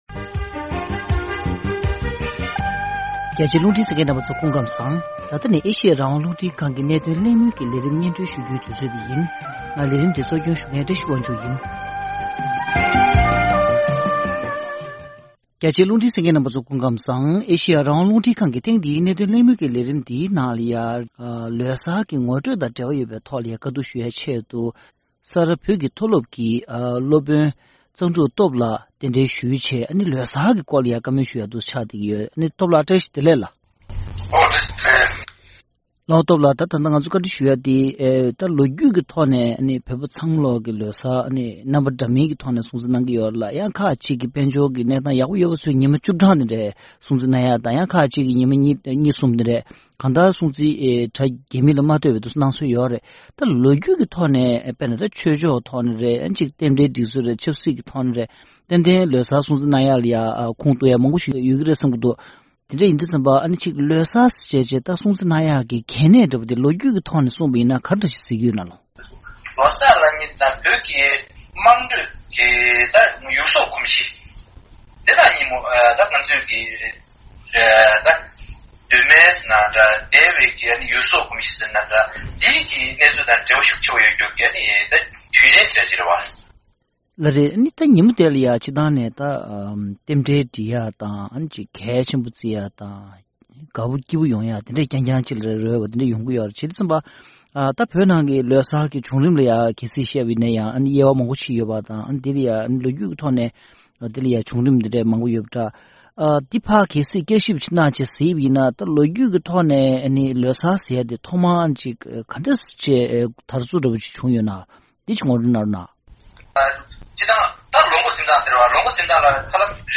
ཐེངས་འདིའི་གནད་དོན་གླེང་མོལ་གྱི་ལས་རིམ་ནང་།